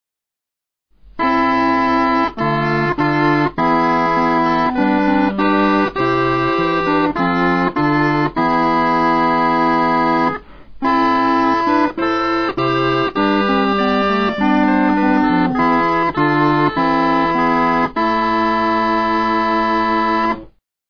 The picture shows a set of cornamusen (which are essentially straight crumhorns) consisting of a bass, two tenors, an alto and a soprano.
The harder wood gives this instrument a brighter tone.
They are multitracked recordings the first two arranged for two sopranos, an alto and a bass, the third for an alto two tenors and a bass.